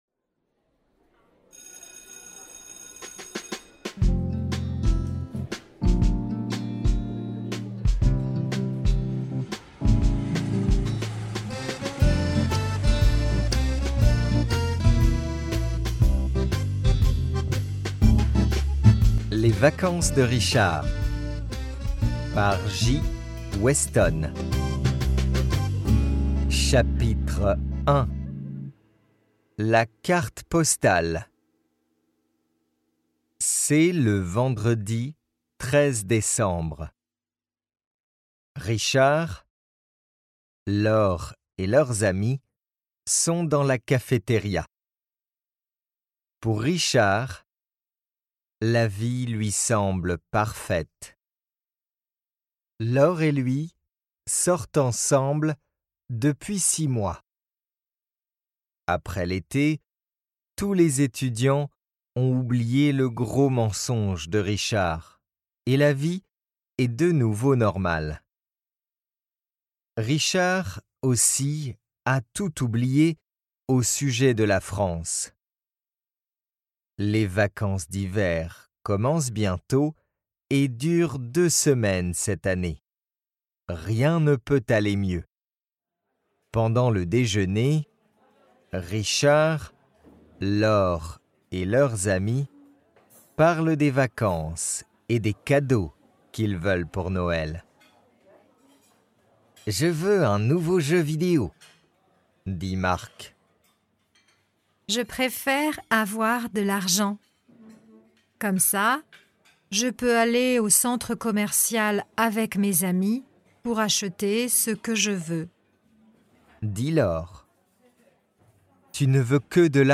Each audiobook contains original music, sound effects and voice acting from Native French speakers to ensure a memorable experience for your students!
AudioBook Sample